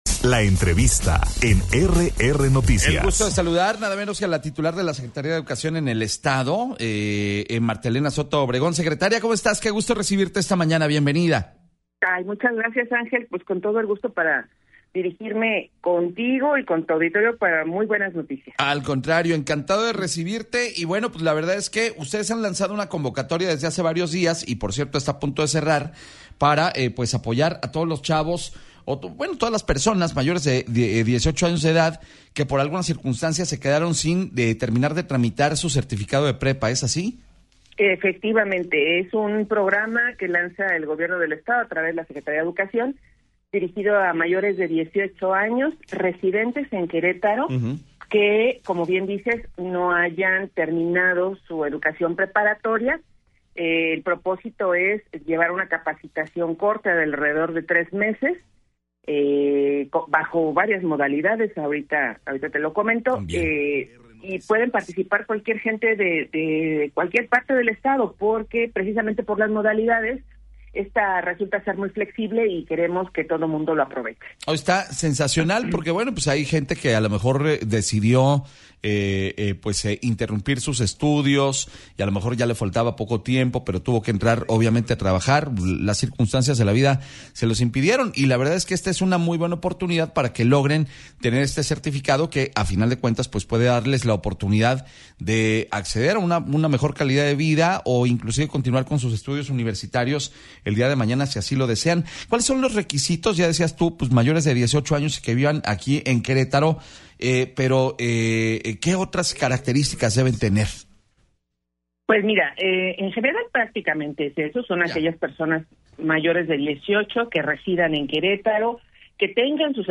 EntrevistasOpinión